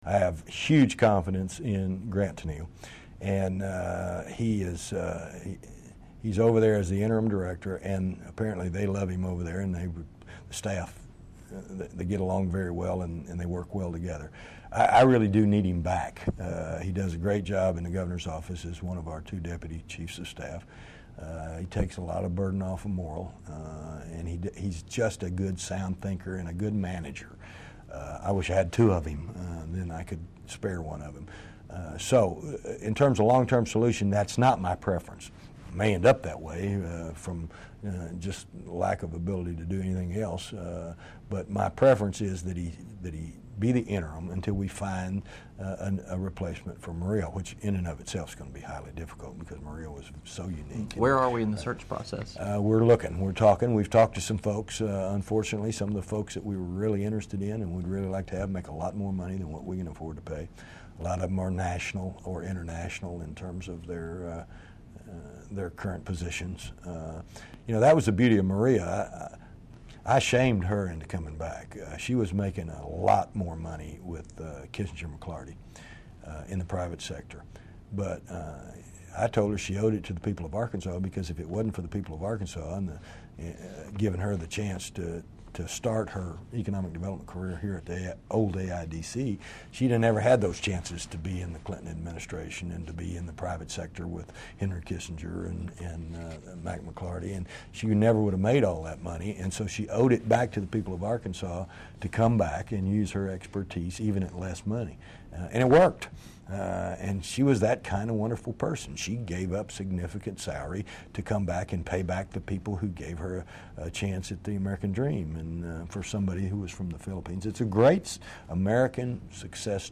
Governor Mike Beebe Discusses Jobs, Politics, etc.